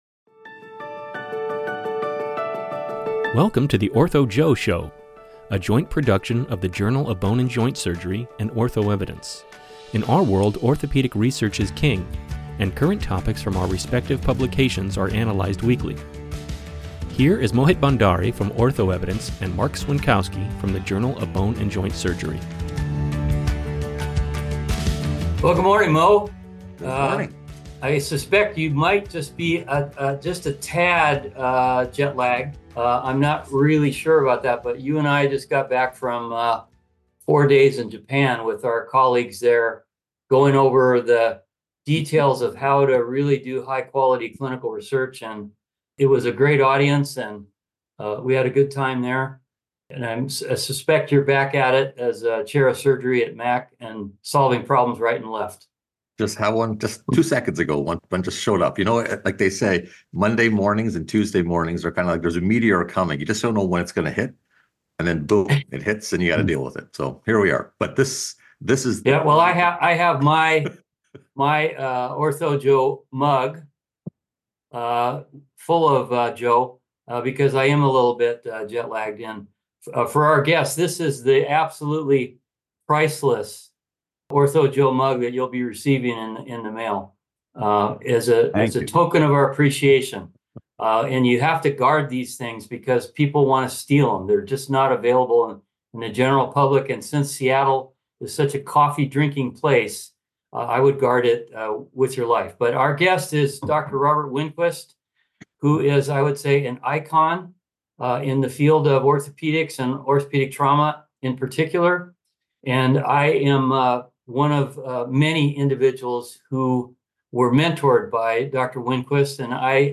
wide-ranging discussion